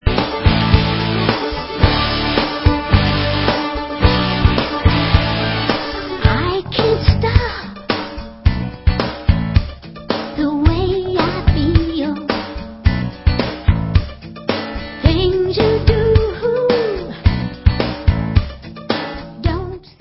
sledovat novinky v oddělení Rockabilly/Psychobilly